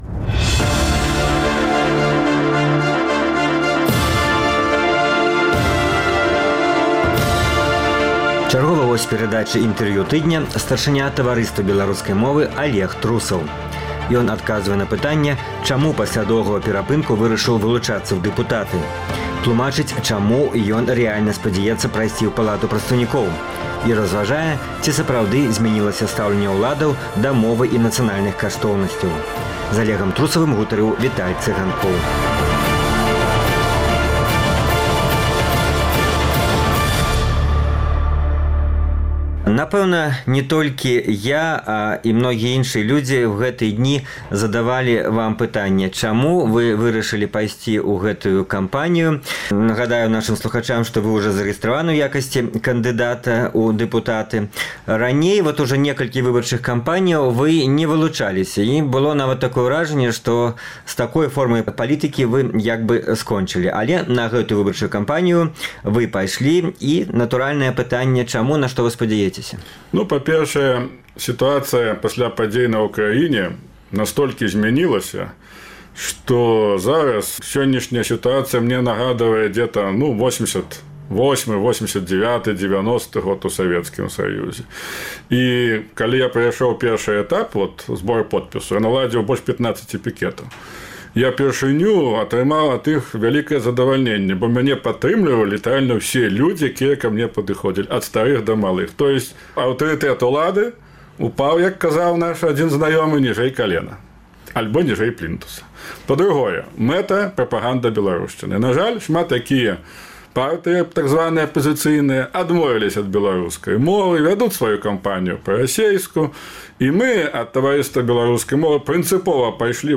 Чарговы госьць перадачы Інтэрвію тыдня